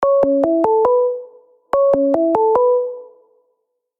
Neizvestnyj-Hangouts_Video_Call-spaces.r.mp3